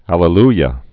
(ălə-lyə)